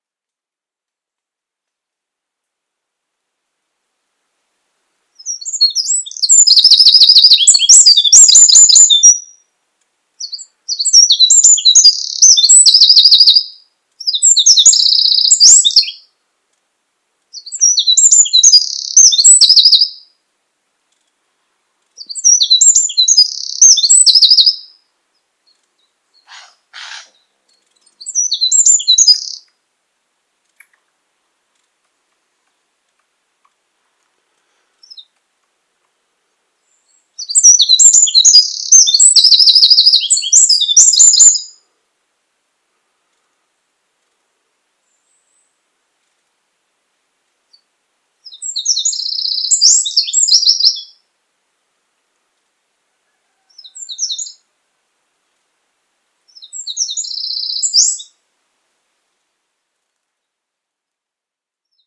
Fågel- och däggdjursläten
Ibland har ljudinspelningen gjorts med kameran.
Gärdsmyg   Lidhem 27 juli 2027